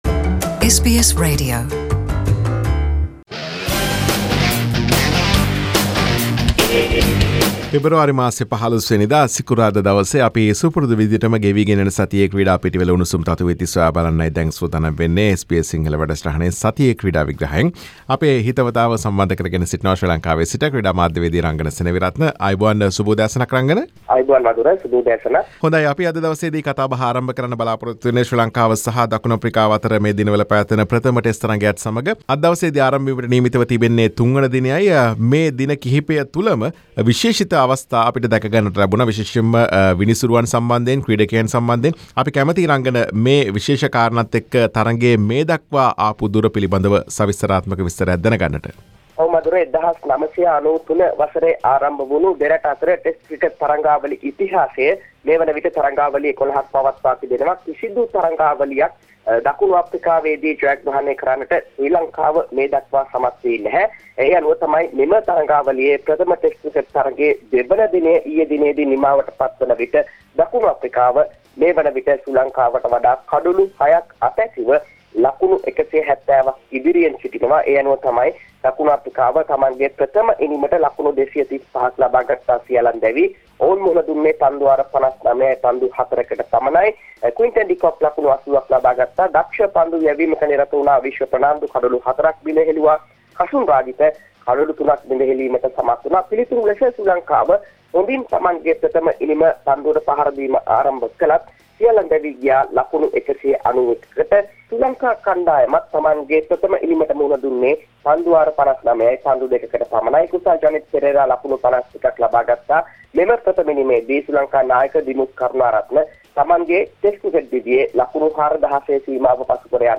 Today’s content: Sri Lanka tour in in South Africa, Sri Lanka vs South Africa women's ODI series, SLC Election, Sports Minister met senior cricketers, Bronze medal for Sri Lanka in USA half marathon and Big Bash League 2018/19. Sports journalist